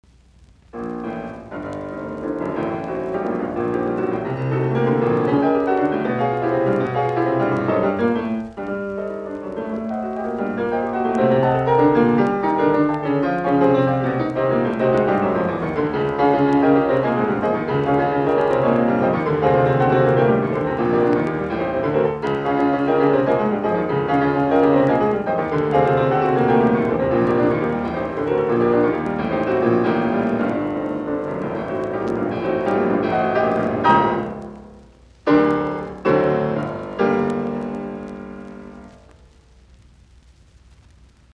in g minor, Molto agitato